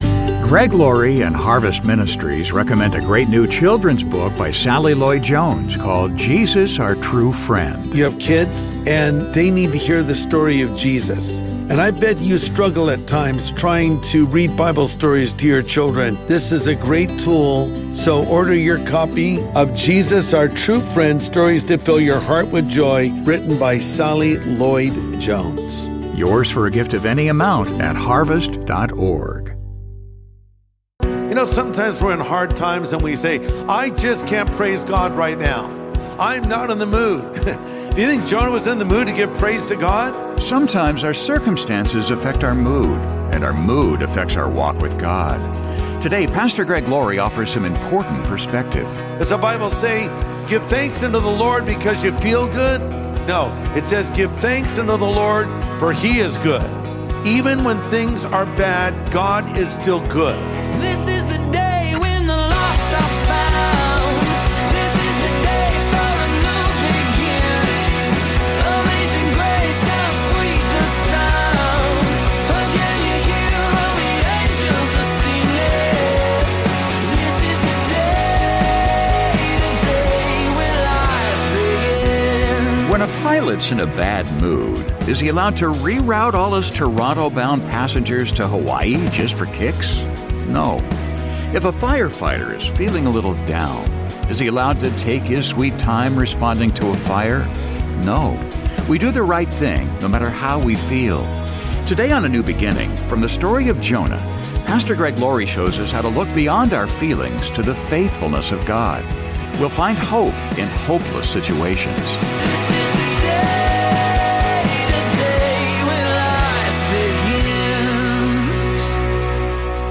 No. We do the right thing noÂ matter how we feel. Today on A NEW BEGINNING, from the story of Jonah, PastorÂ Greg Laurie shows us how to look beyond our feelings to the faithfulness of God.